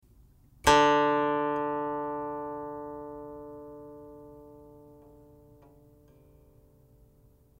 guqin005A.mp3